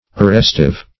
arrestive - definition of arrestive - synonyms, pronunciation, spelling from Free Dictionary
Arrestive \Ar*rest"ive\ (-[i^]v), a.